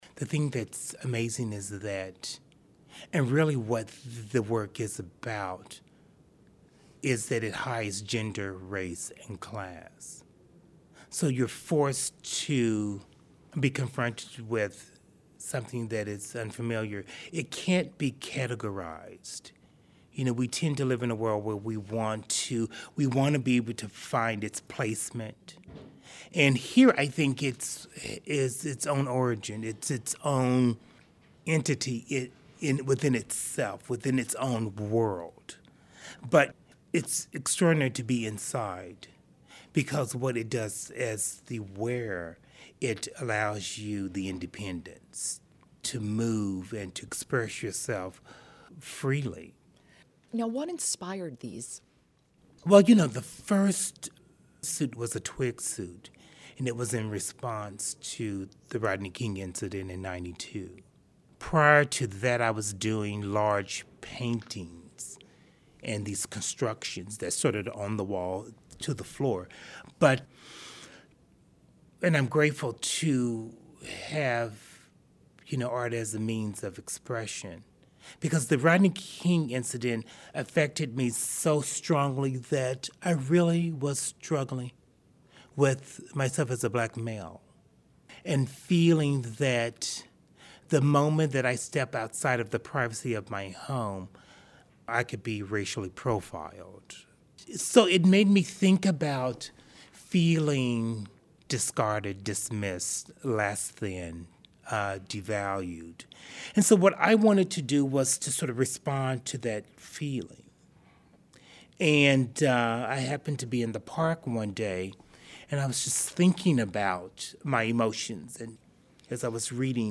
In this excerpt from the podcast, Cave talks about what it's like to actually wear one of these fabulous creations.